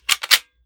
7Mag Bolt Action Rifle - Slide Up-Back 003.wav